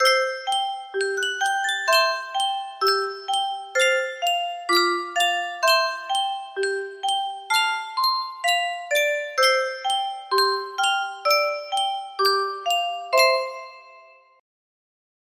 Sankyo Music Box - Our Director PPA music box melody
Full range 60